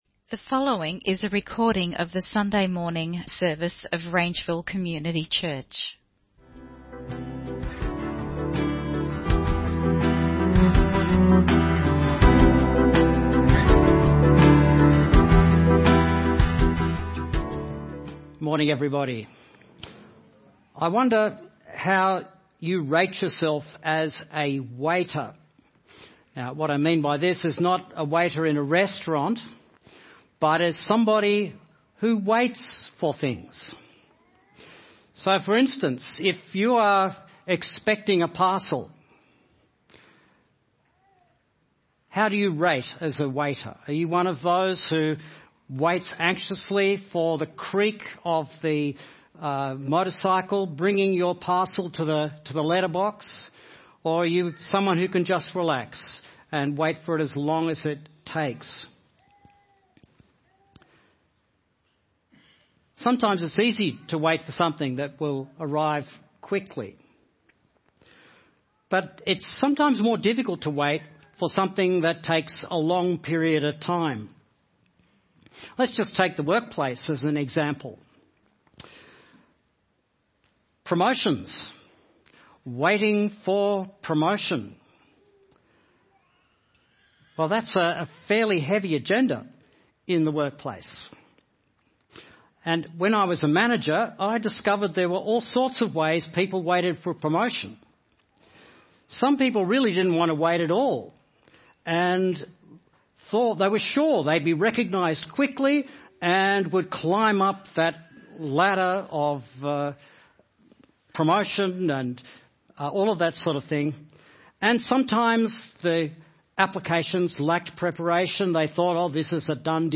Waiting for Jesus (Sermon Only - Video + Audio)